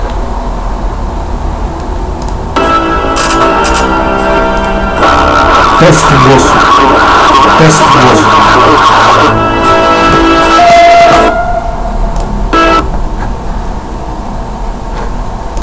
Counter Strike 1.6 - trzeszczący mikrofon
Nagrania są z menu Counter Strike-a , nie z serwera jakby co.
W drugim nagraniu słychać wyraźnie mój głos tylko że razem z szumem.
Mikrofon - Mikrofon wewnętrzny w laptopie